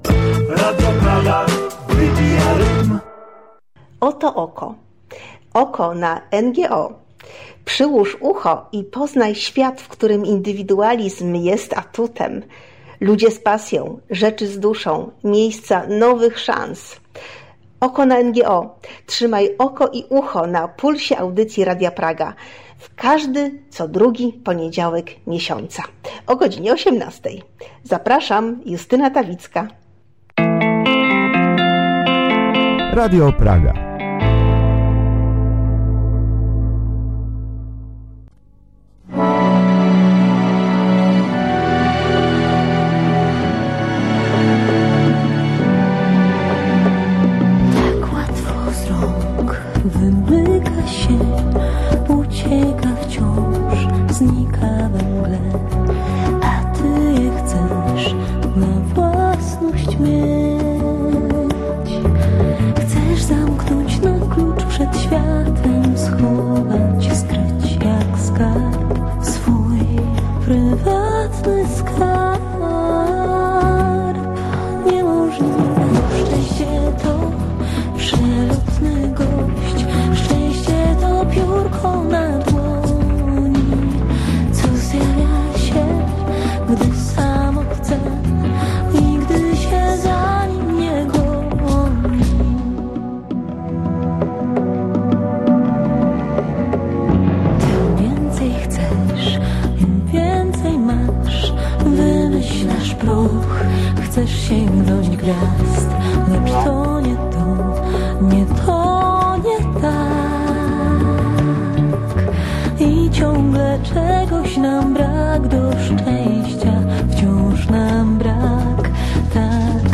Posłuchajcie tej rozmowy i pomyślcie, że szczęście to przelotny gość.